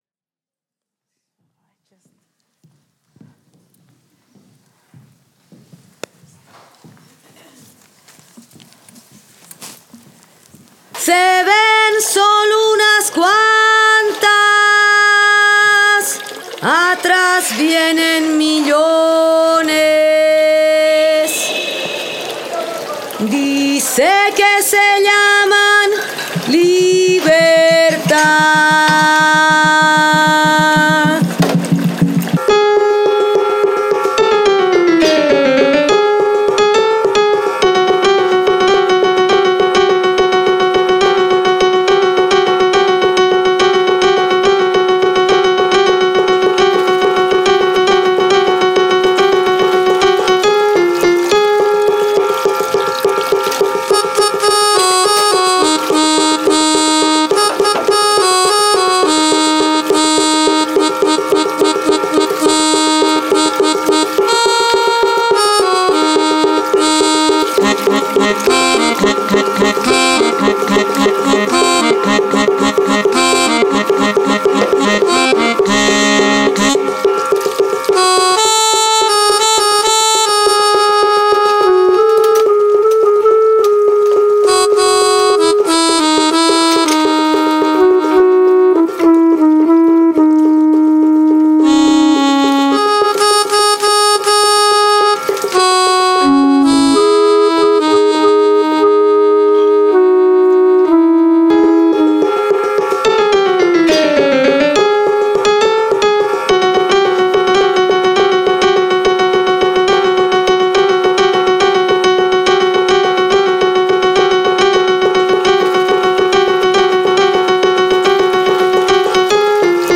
A sort of prayer and conference, a sort of scream and dialogue – a monologue and declaration at the time, addressing how we can build complicity with one another.